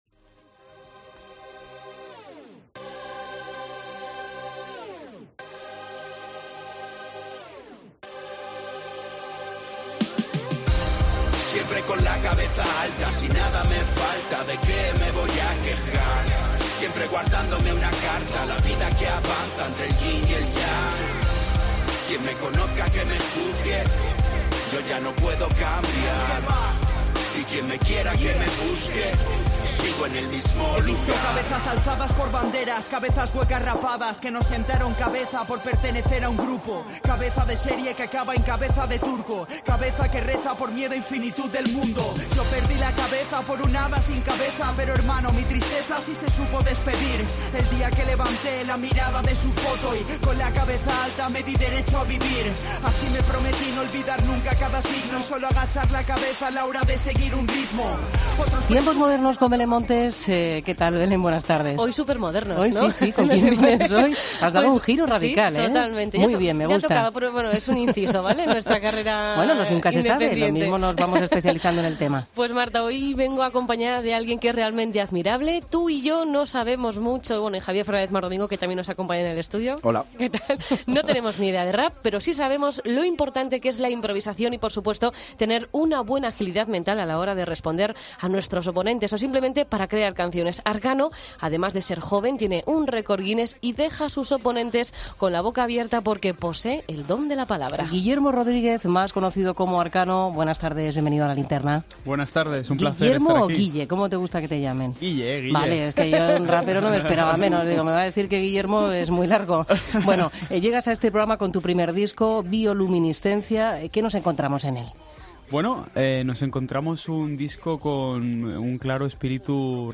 Entrevista a Arkano, 1 de noviembre de 2017